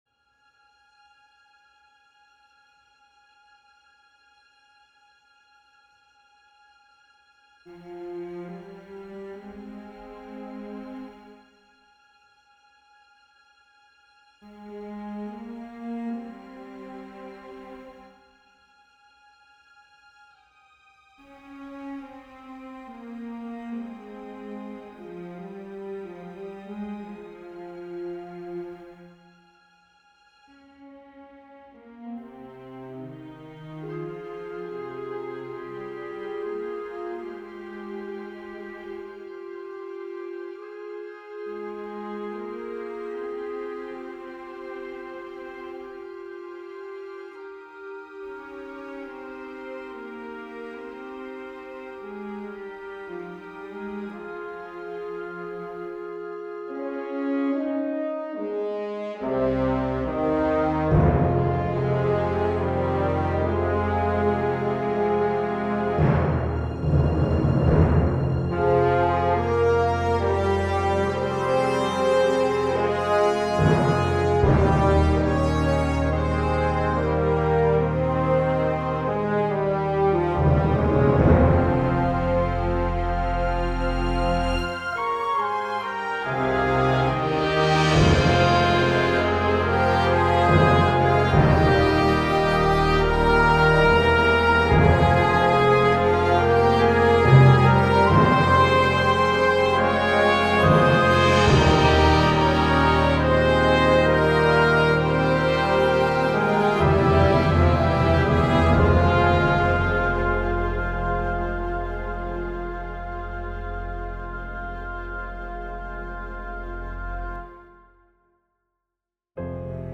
Thoughts on my current rough-drafted ideas / progress on a new symphony orchestral score I'm working on?
There are still some areas where the notation hasn't been filled in all the way, for some measures/chords. So disregard anywhere that feels "thin" :)